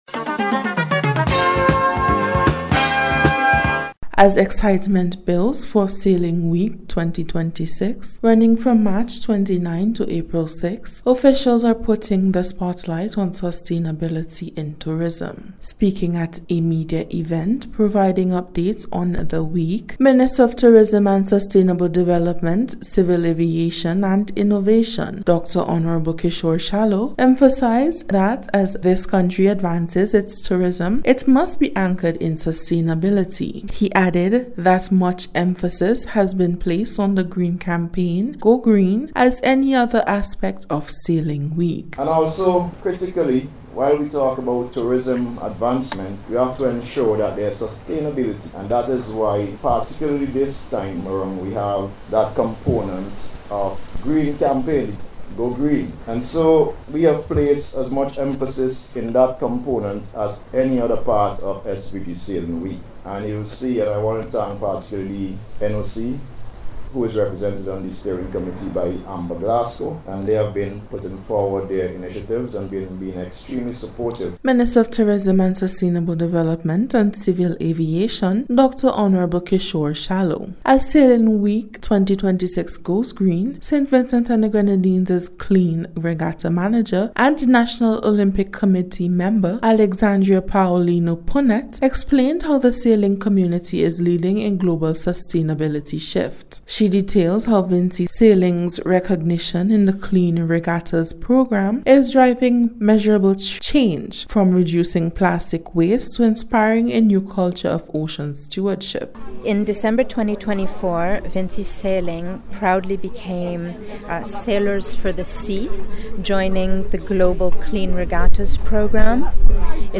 In Today’s special report officials underscore efforts to reduce waste, protect the ocean, and promote green practices across the sailing community.